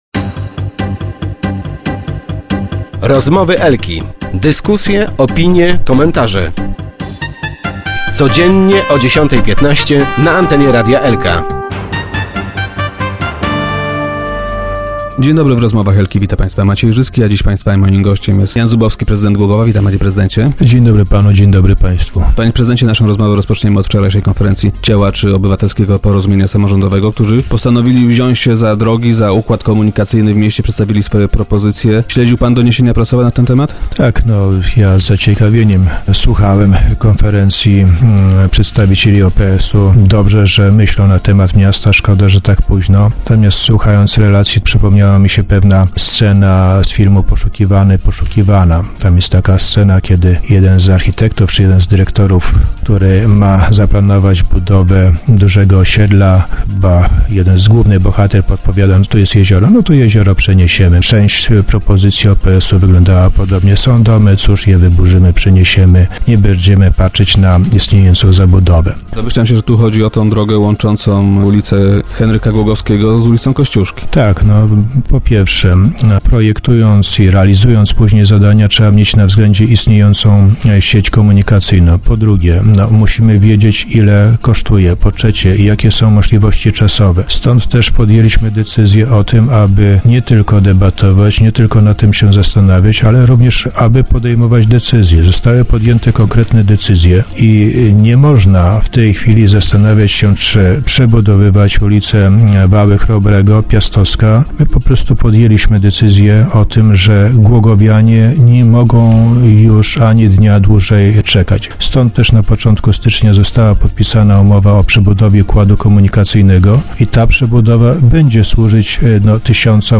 W przyszłym roku głogowianie będą widzieli ich konkretne efekty - powiedział prezydent w radiowym studio.